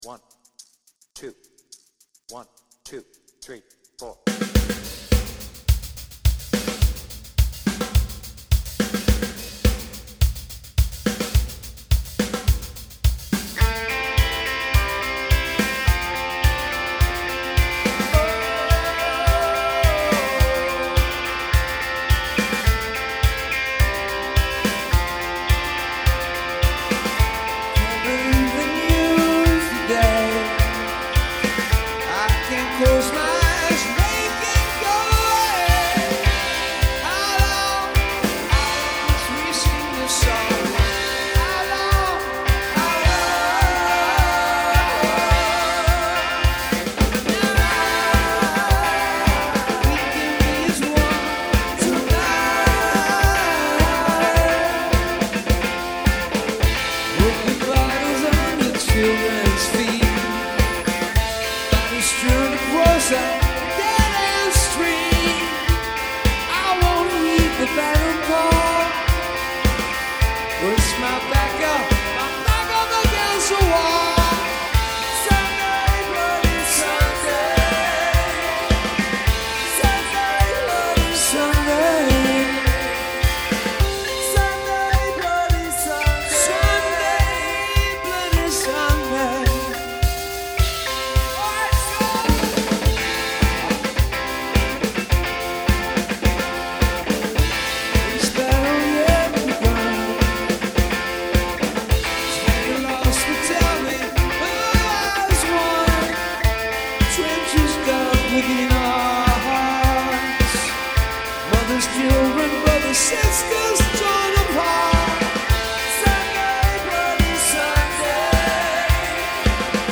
BPM : 106
Tuning : Eb
Based on the 360° version + extended outro